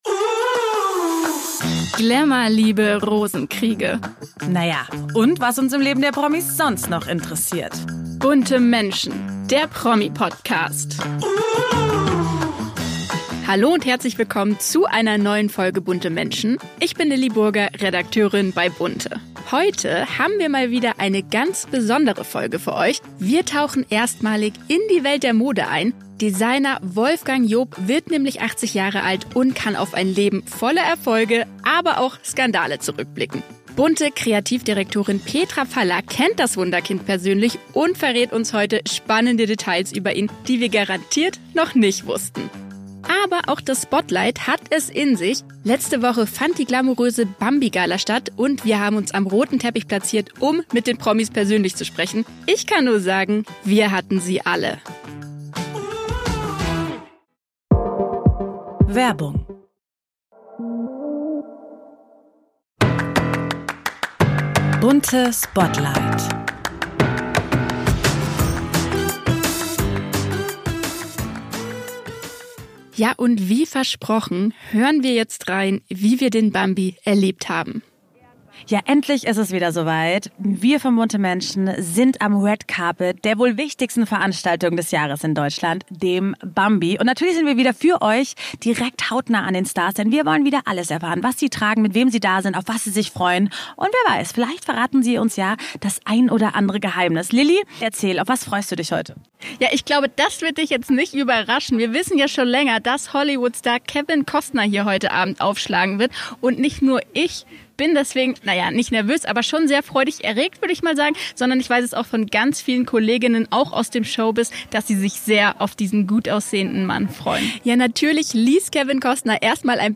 Außerdem im Spotlight: Spannende Einblicke von der BAMBI-Gala!